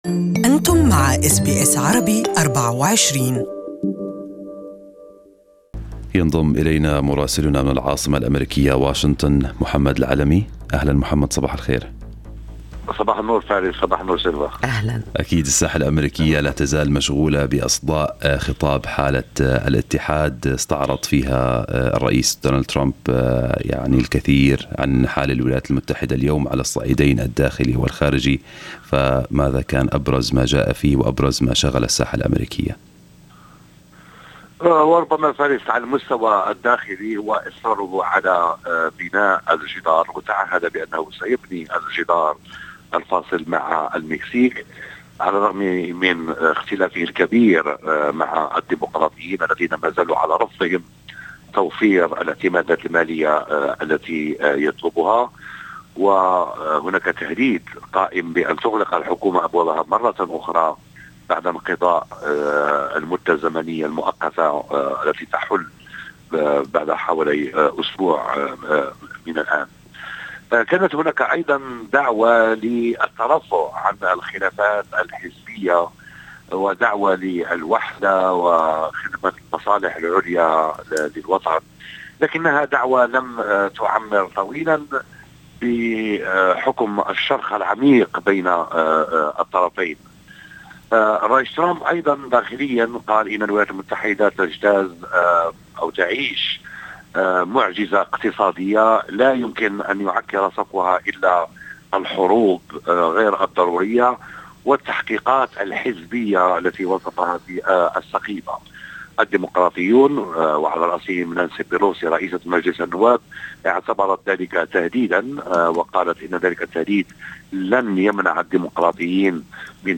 Listen to the full report from Washington in Arabic above